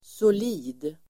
Uttal: [sol'i:d]